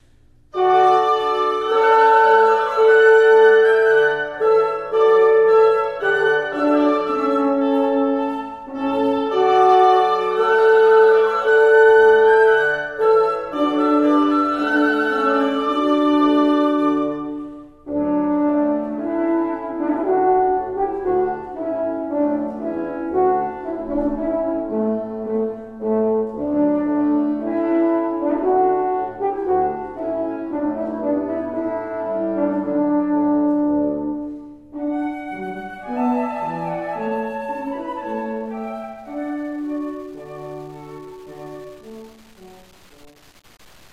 Popular prelude and ceremony music for weddings